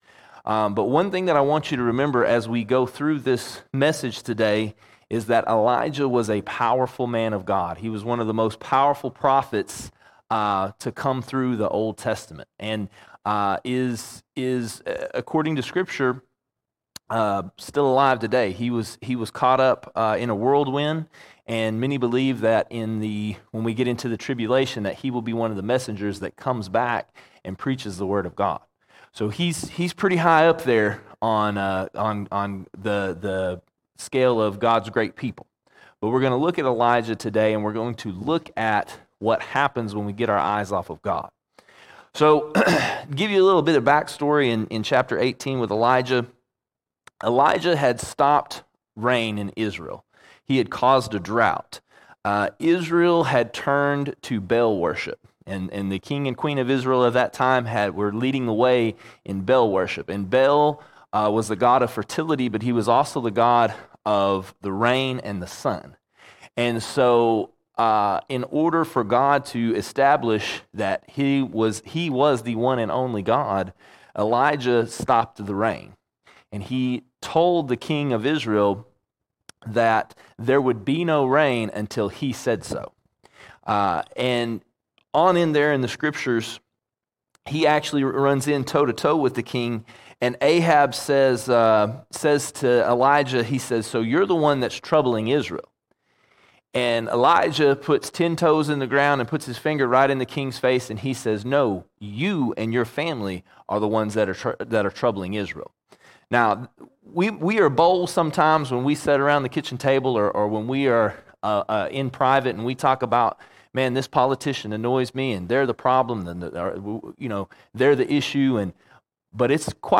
15 October 2023 Series: Sunday Sermons Topic: deliverance All Sermons Saved From A Dark Place Saved From A Dark Place As Elijah of old, modern Christians can find themselves in dark places, But God delivers.